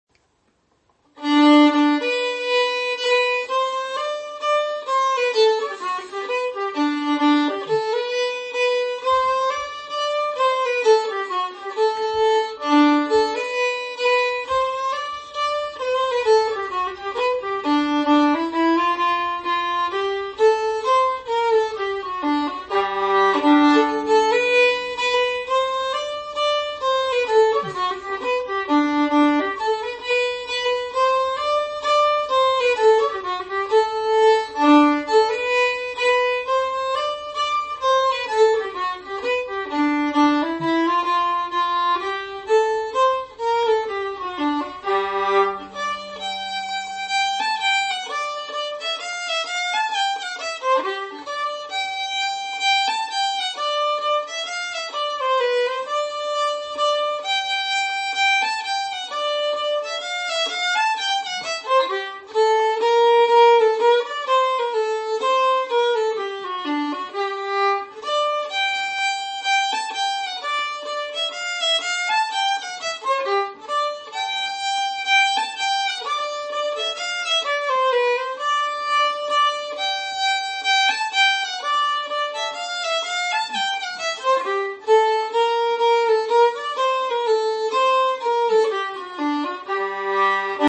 Key: G
Form: Waltz
M: 3/4